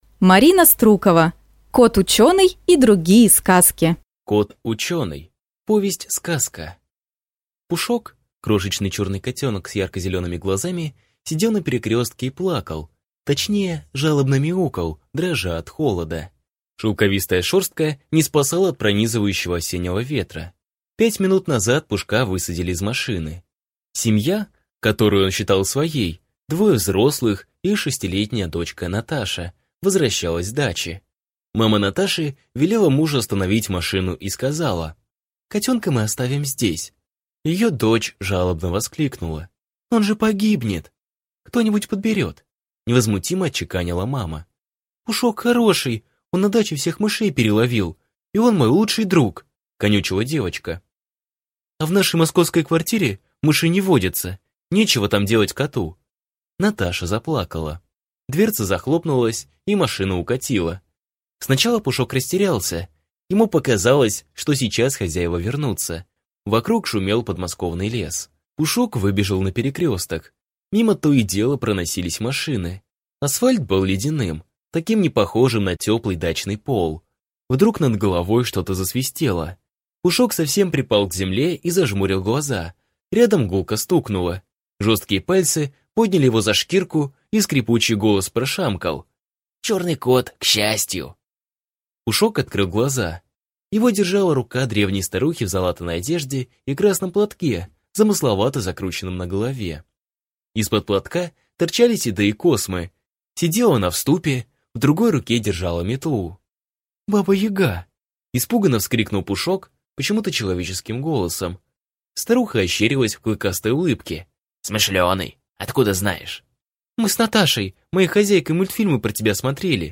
Аудиокнига Кот учёный и другие сказки | Библиотека аудиокниг
Прослушать и бесплатно скачать фрагмент аудиокниги